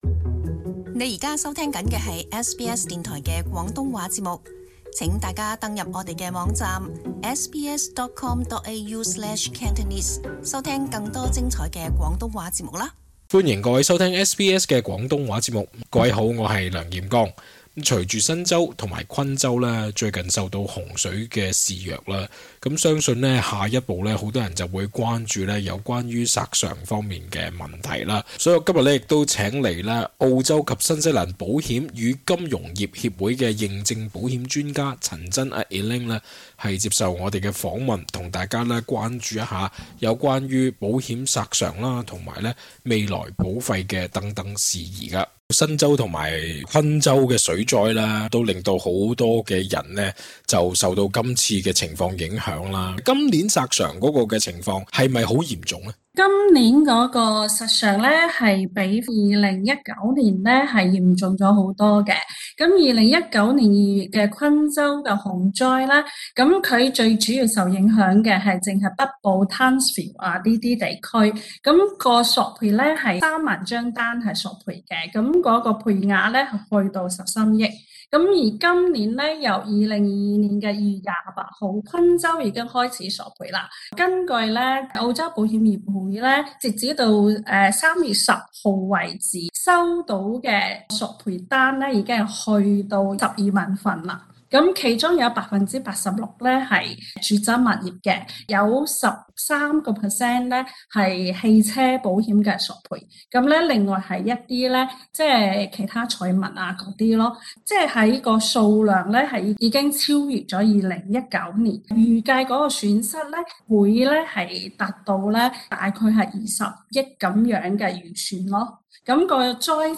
更多访问内容请留意足本录音。